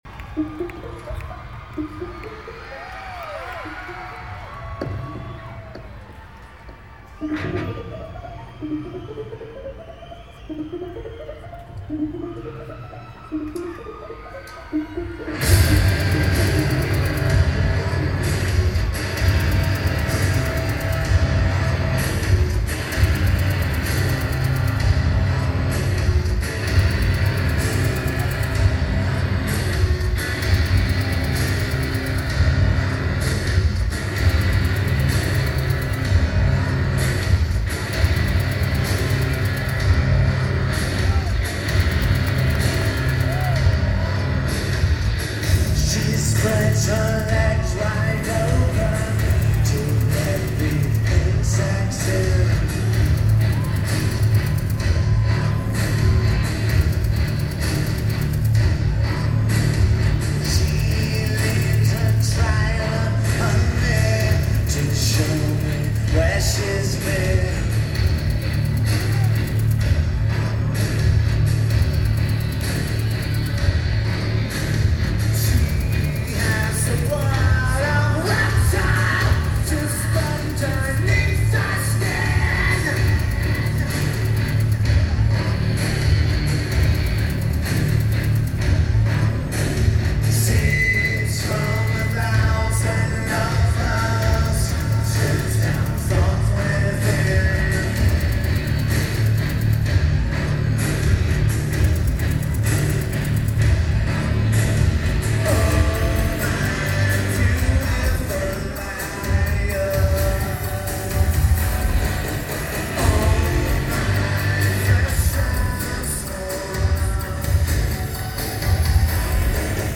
Lakewood Amphitheatre